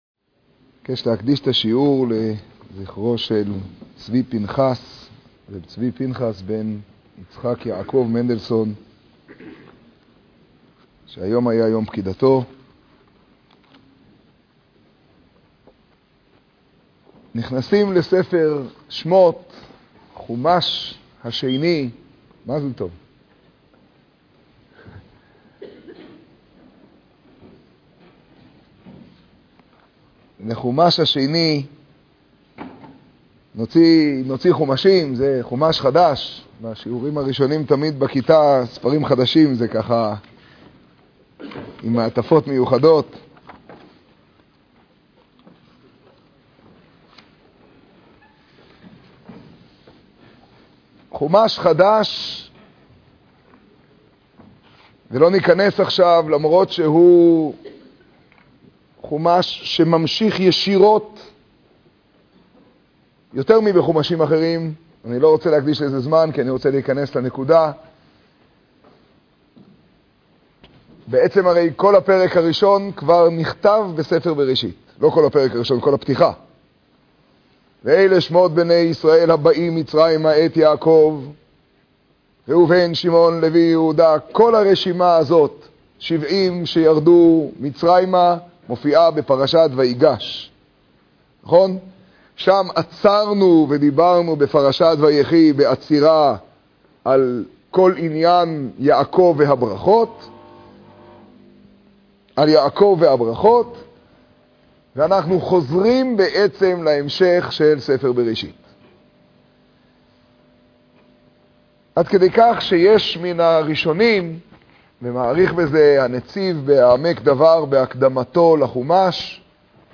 האזנה / משמר חלק ג' קטגוריה: שיעור , שיעור בירושלים , תוכן תג: חומש , שמות , תשעב → כי גרים ותושבים אתם עמדי.